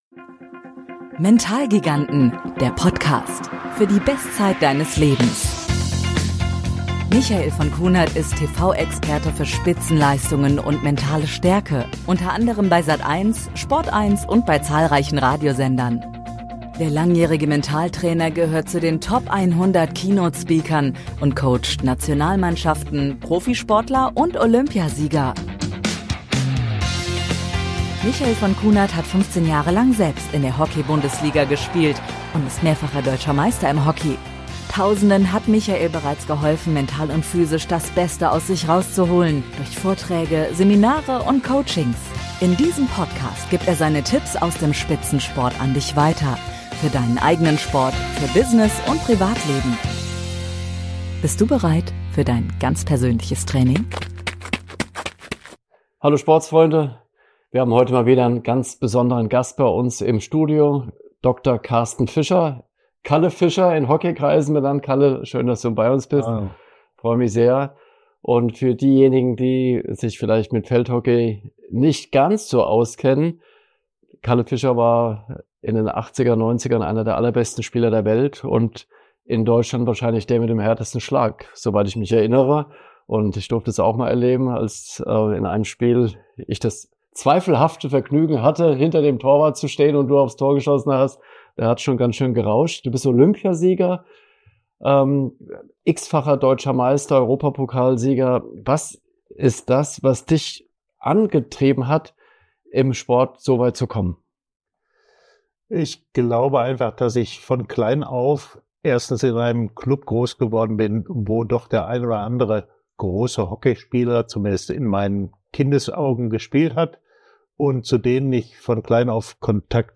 Zum Abschluss teilt er seinen Wunsch für die Zukunft unserer Gesellschaft. Ein Gespräch voller Inspiration, das zeigt, wie Disziplin, Teamgeist und Verantwortung uns voranbringen können.